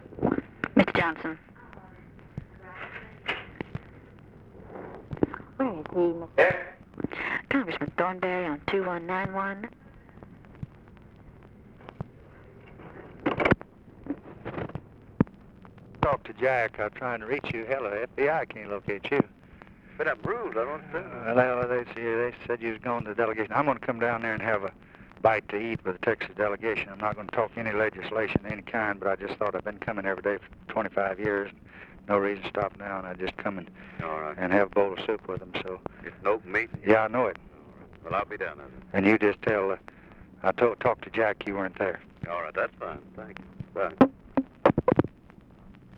Conversation with HOMER THORNBERRY, December 4, 1963
Secret White House Tapes